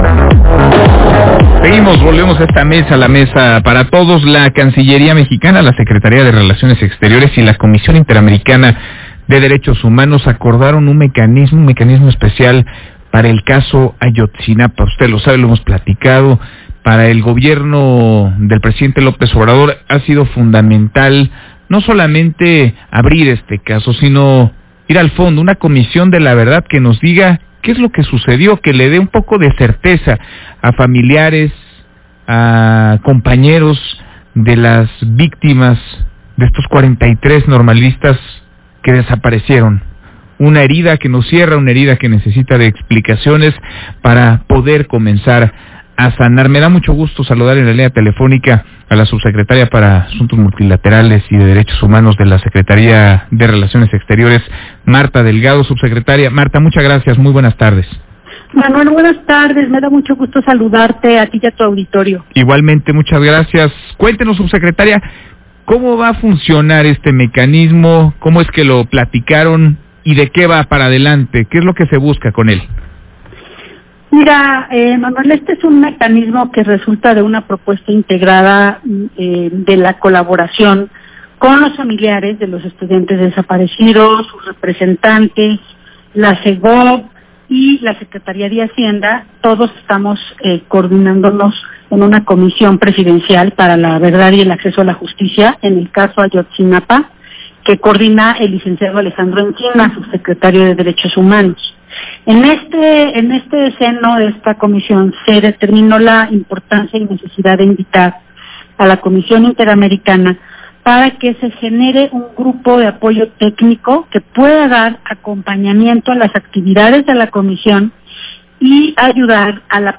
[Audio] Entrevista en MVS Noticias con Manuel López San Martín sobre colaboración con CIDH en Caso Ayotzinapa